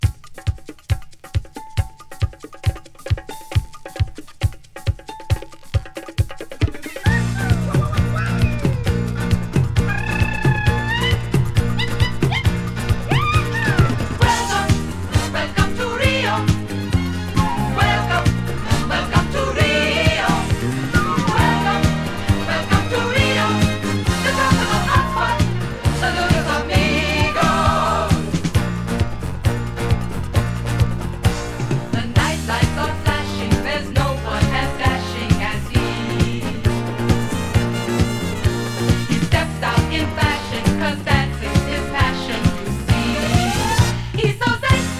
Pop, Disco, Novelty　USA　12inchレコード　33rpm　Stereo